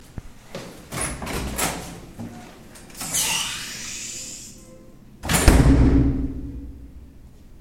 metal door